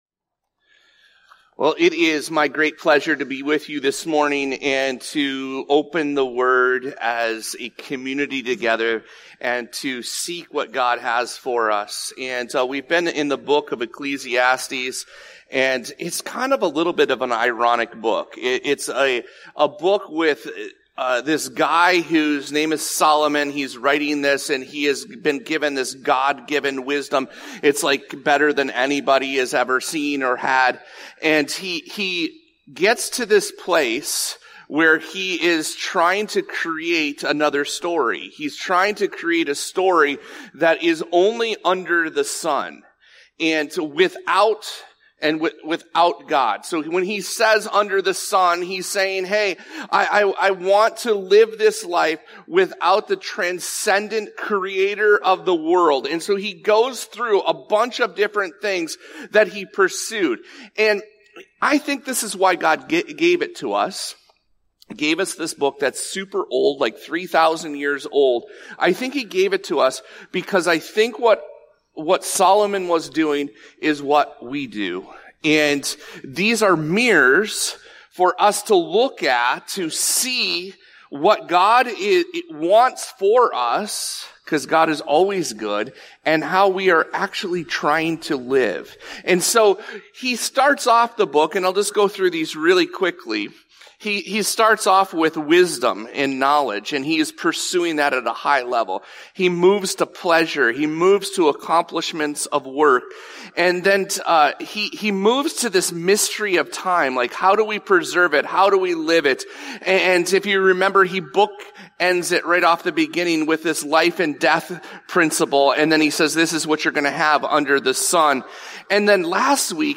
The Life that Sets Us Free (Matt. 6:26).This episode of the Evangel Houghton podcast is a Sunday message from Evangel Community Church, Houghton, Michigan, October 19, 2025.